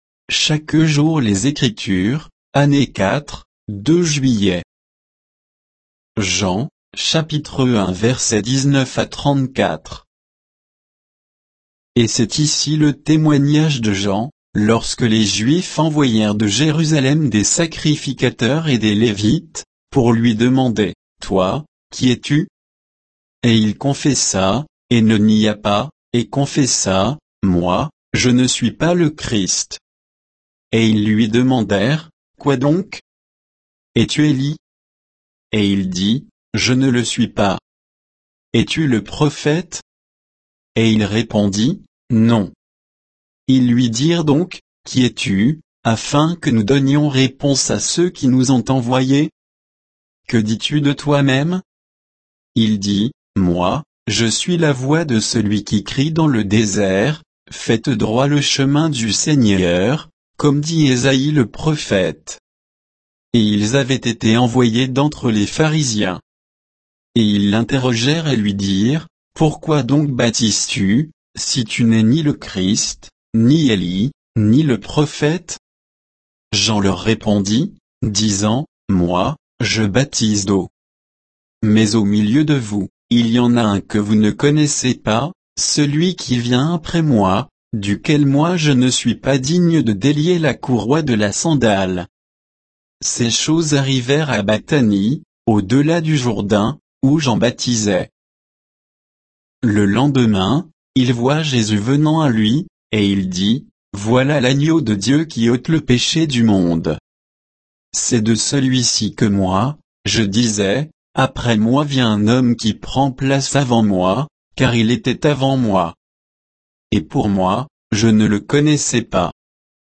Méditation quoditienne de Chaque jour les Écritures sur Jean 1, 19 à 34